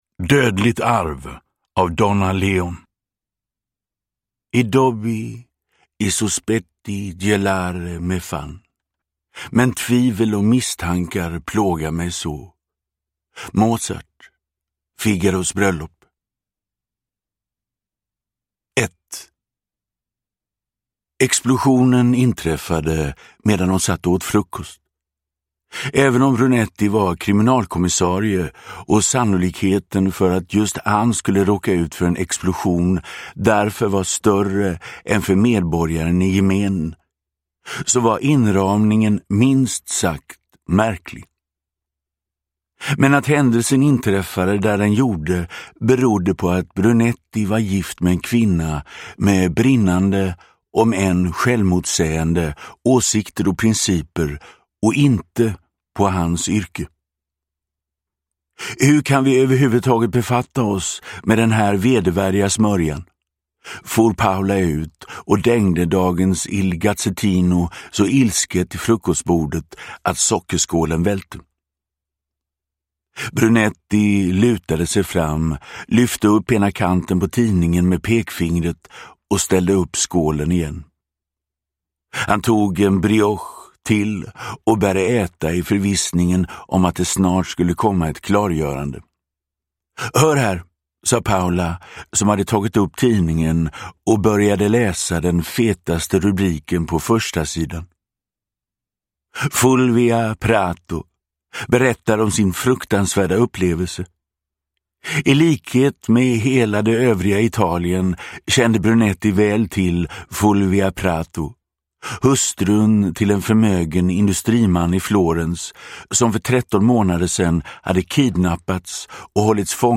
Uppläsare: Magnus Roosmann